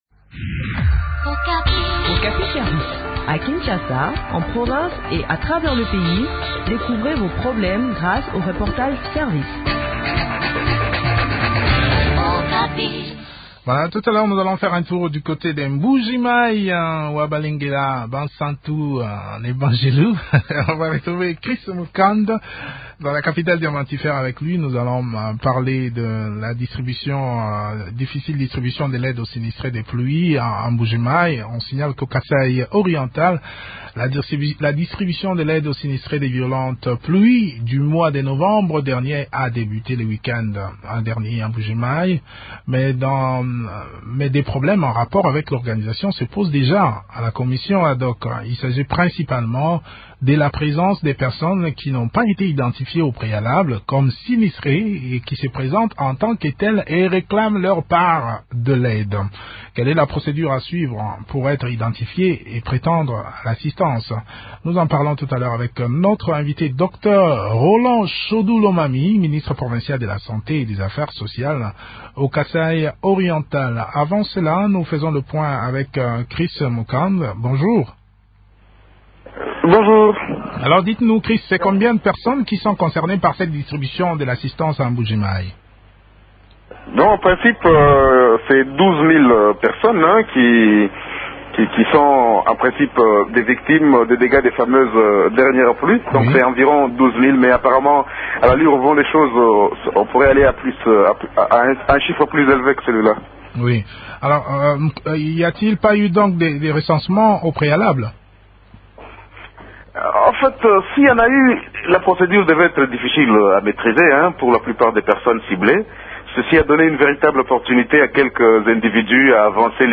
s’entretient sur la question avec Roland Shodu Lomami, ministre provincial de la Santé et des Affaires sociales.